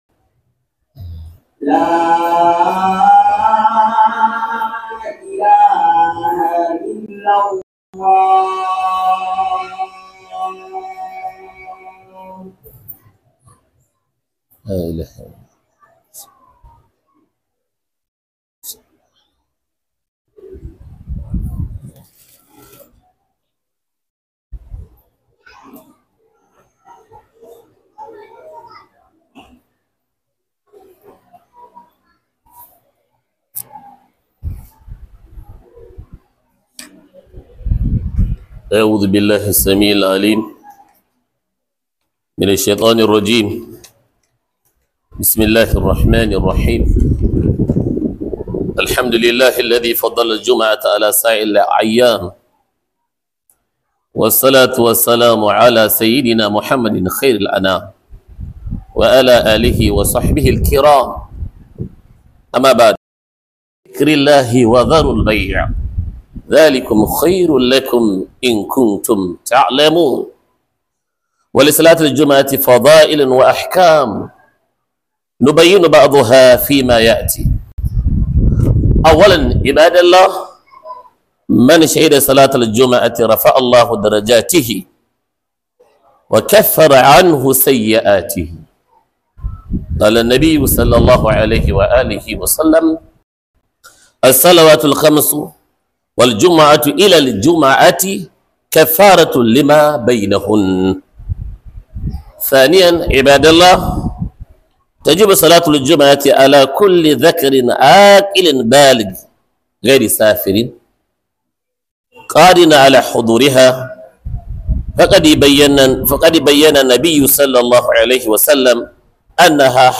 Huduba - Falalar Ranar Juma'a - Huduba